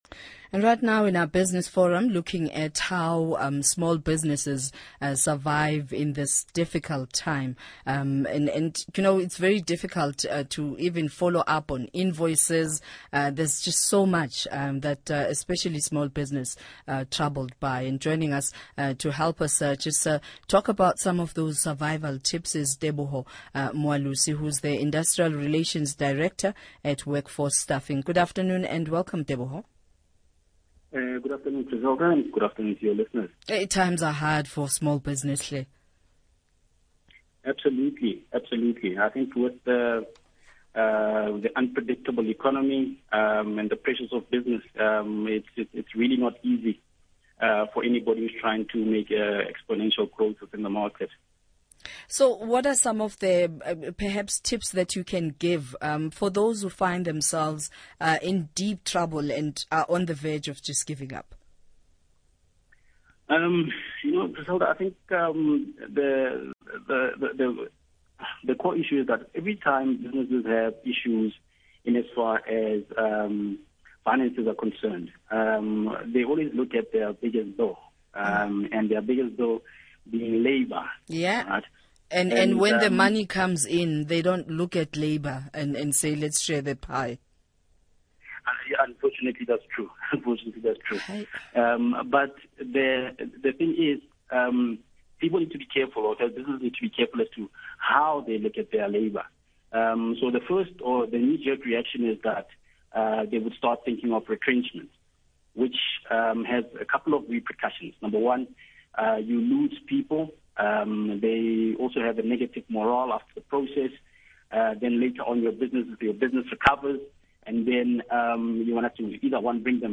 SAFM Interview